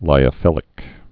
(līə-fĭlĭk)